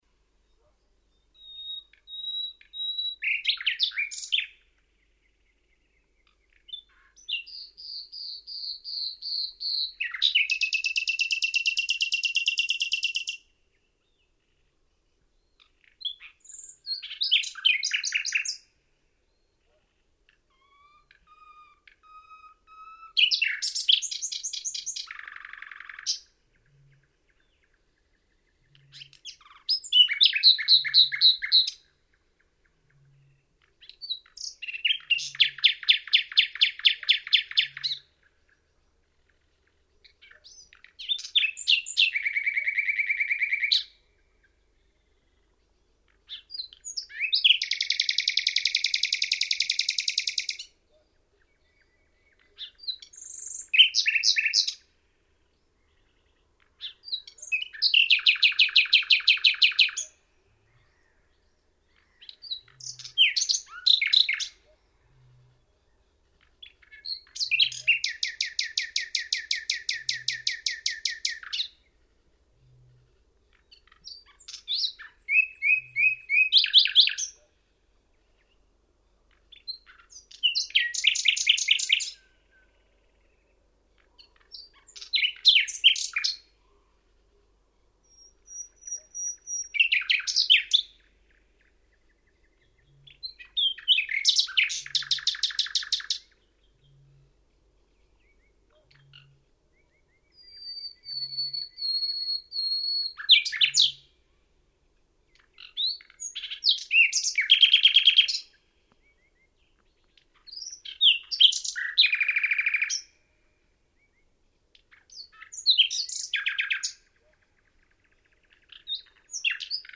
Etelänsatakieli / European Nightingale Luscinia megarhynchos
Vesilahti, Suomi, Finland 2.6.2004 (MP3, 2MB) Laulua / Song